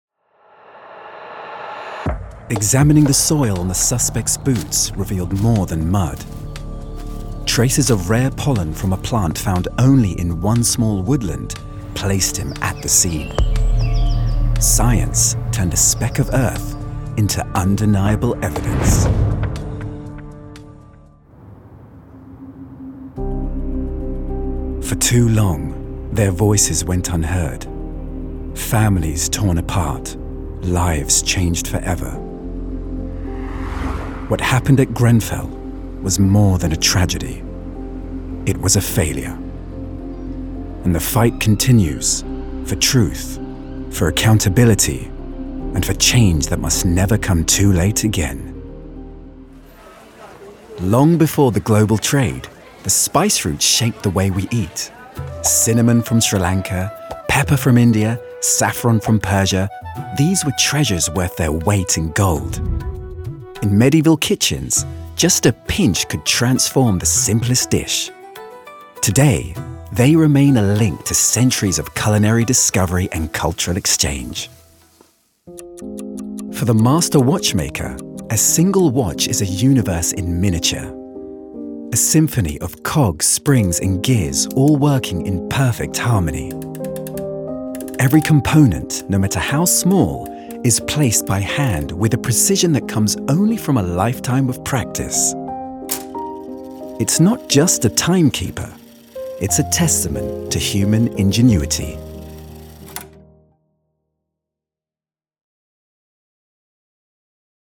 Narration Showreel
Male
Neutral British
Confident
Friendly